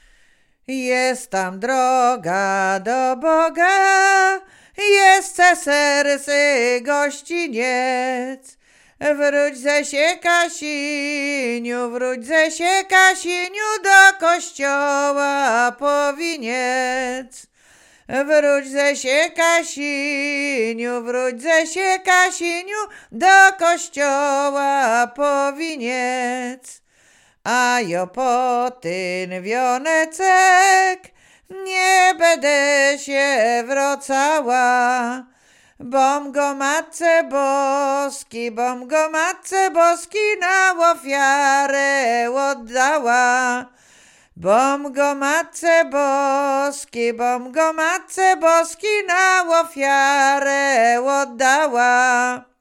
Łowickie
Weselna
weselne wesele na wyjazd z kościoła